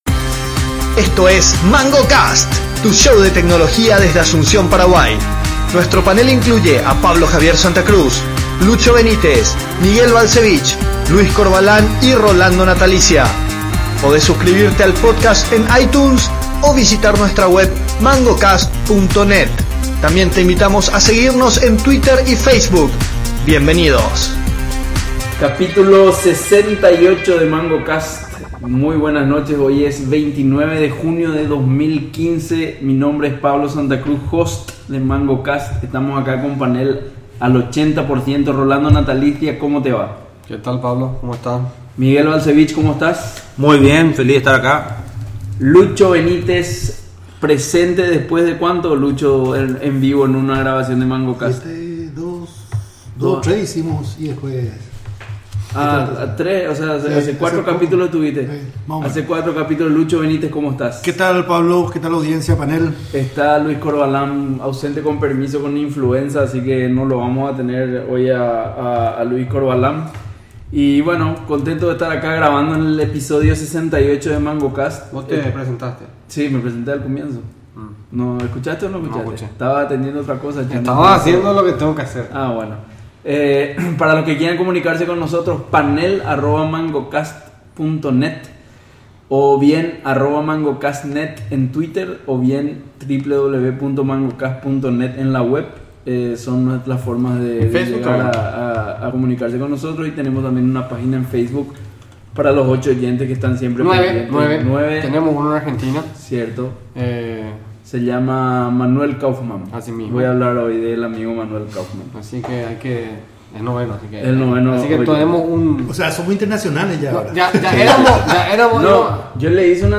Hacer el episodio con solo 3 panelistas presentes definitivamente no es lo mismo.